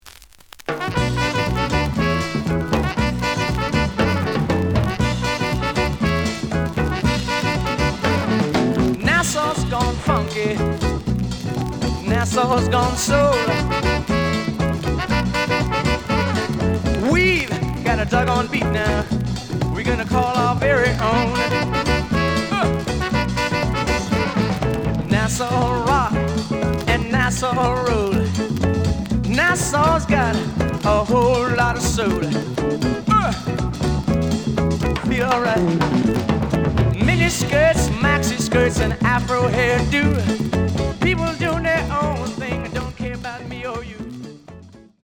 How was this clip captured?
The audio sample is recorded from the actual item. Looks good, but slight noise on A side.)